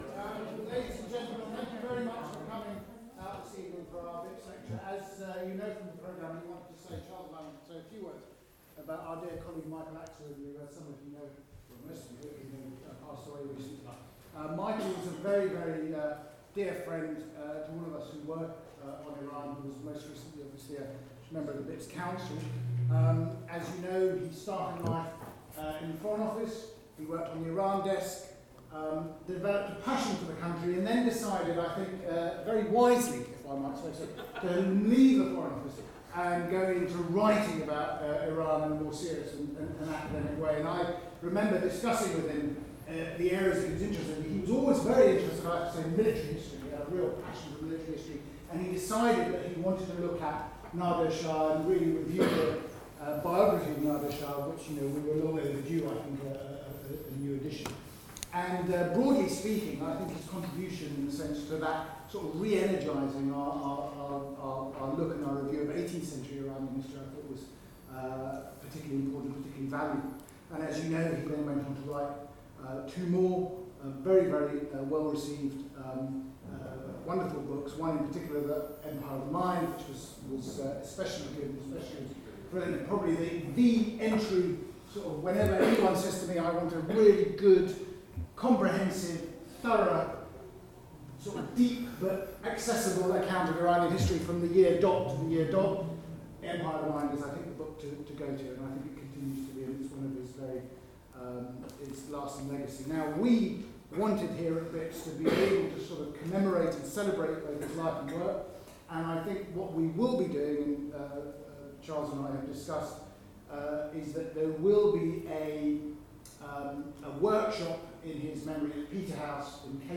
This lecture is divided